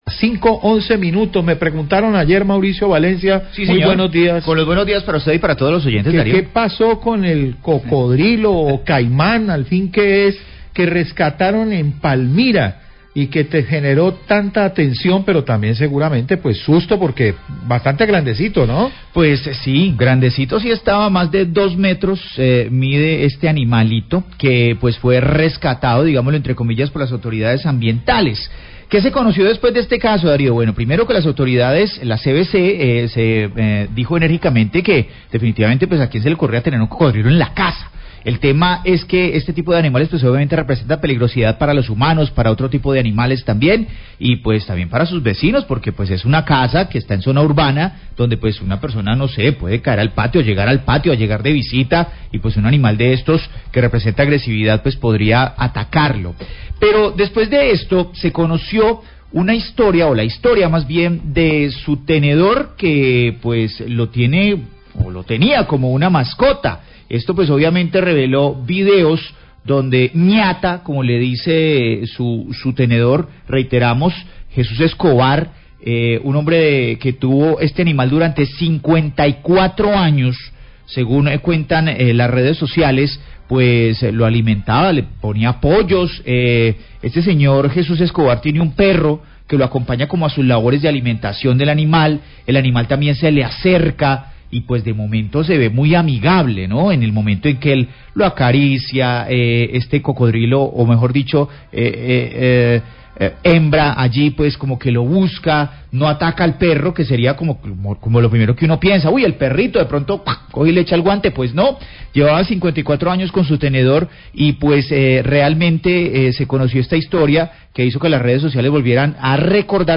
Biólogo CVC entrega reporte sobre salud de caiman rescatado en casa de Palmira,
Radio
Biólogo de CVC entrega la valoración de salud que arroja bajo peso y una masa en la mándibula.